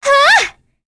Cleo-Vox_Attack2.wav